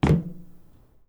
grunk / assets / sfx / footsteps / metal / metal9.wav
metal9.wav